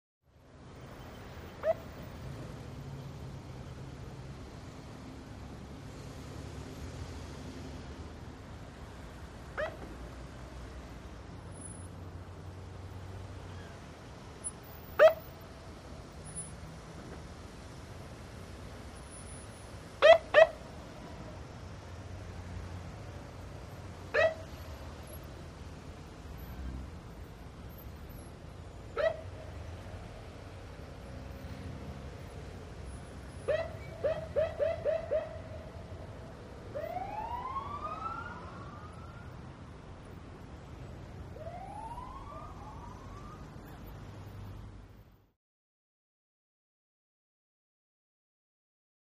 Siren; Very Short Bleeps, Sparse. In And By Close, Away. Light Traffic Ambience.